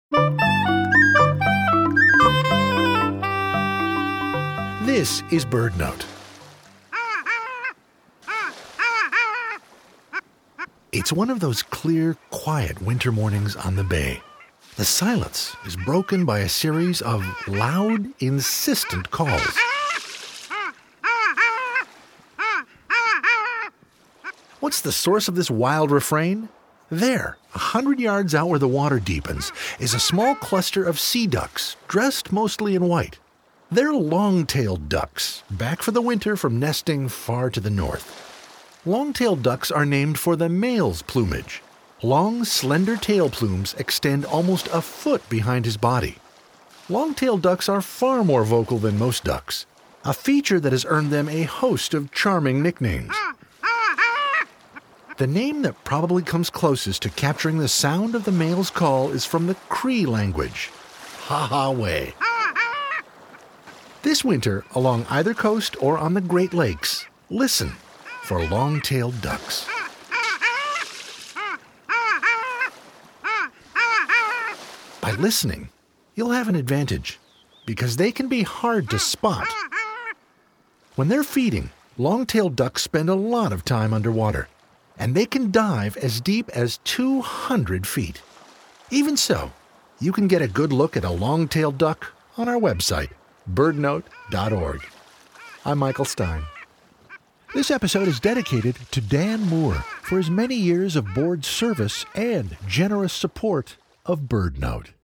These diving ducks spend the winter in deep salt water, often in sheltered bays. Long-tailed Ducks are far more vocal than most ducks, a feature that has earned them a host of charming nicknames, including “John Connally,” “My Aunt Huldy,” and, from the Cree language, “Ha-hah-way.”